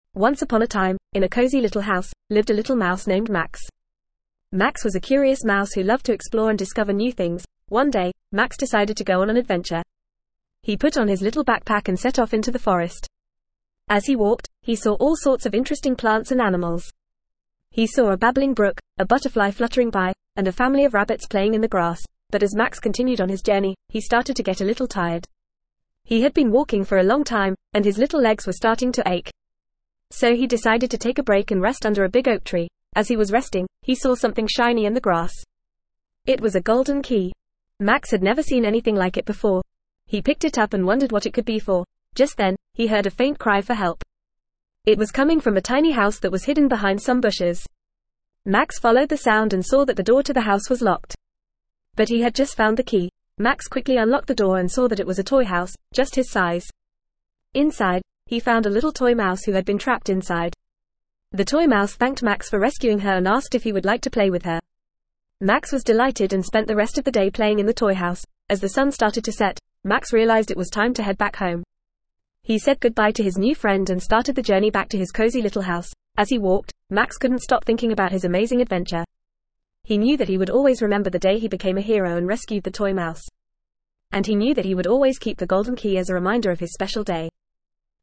Fast
ESL-Short-Stories-for-Kids-FAST-reading-Max-the-Little-Mouse.mp3